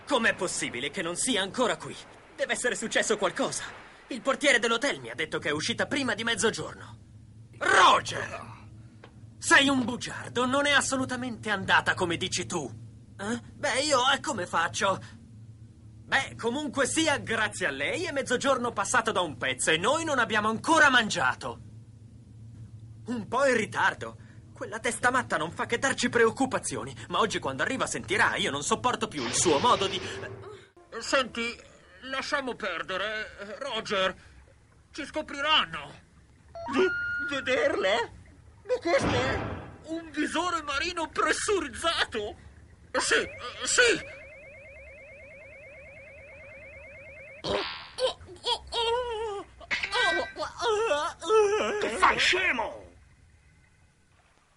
FILM CINEMA
• "Plastic Little" (Voce di Nicol)